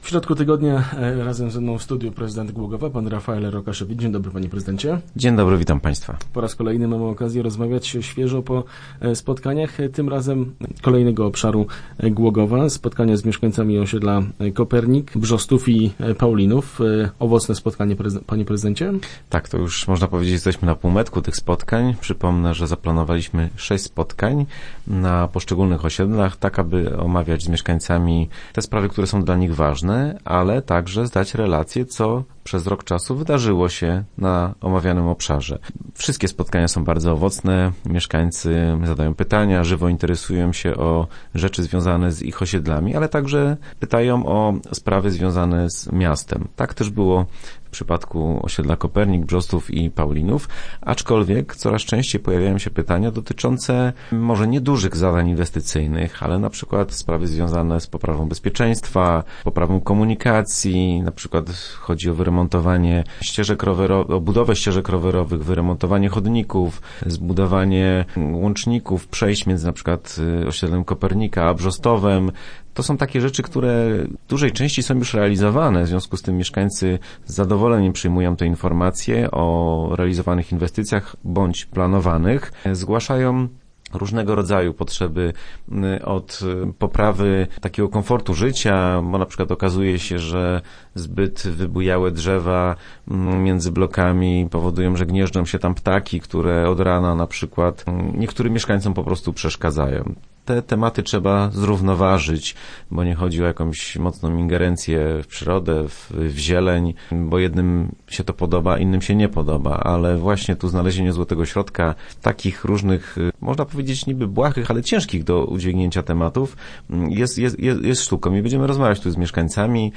Prezydent miasta Rafael Rokaszewicz jest po kolejnej turze spotkań z mieszkańcami Głogowa. W środę w studiu podsumował zebrania na osiedlach i zaprosił na kolejne.